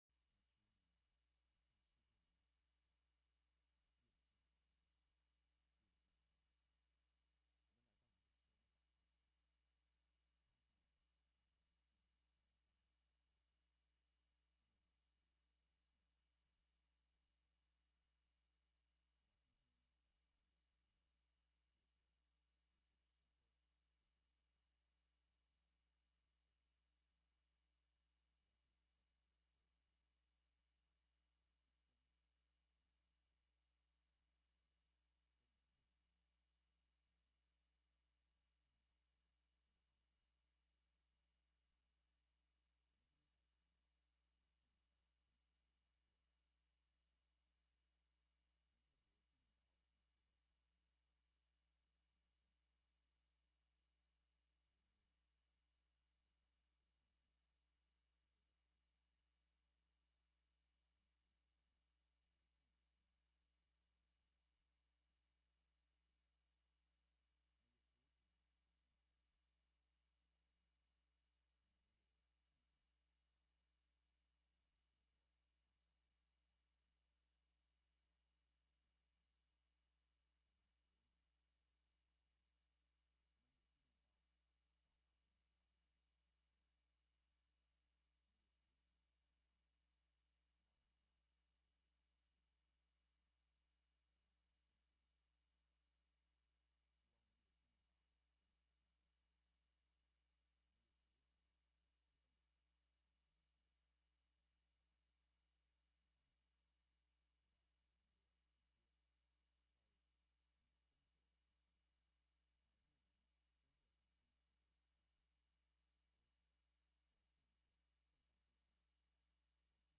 中文讲道